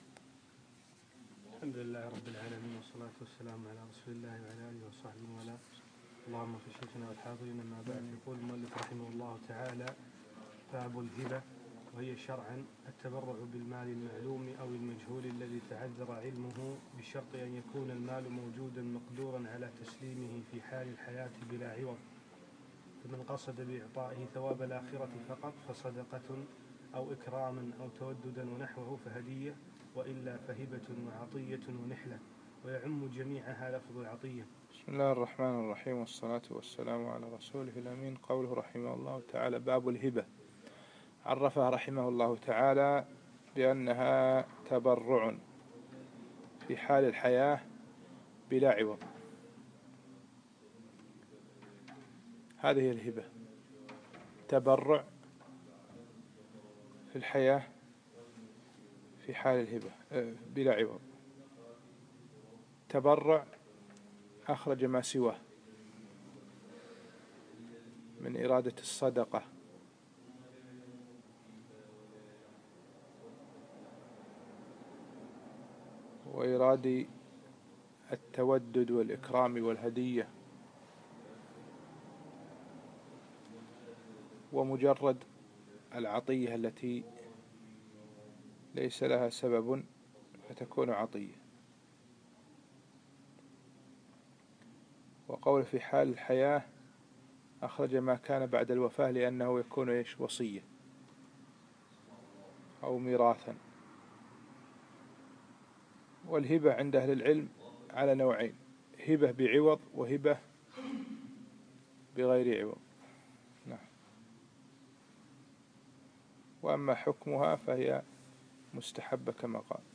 الدرس الخامس والعشرون : باب الهبة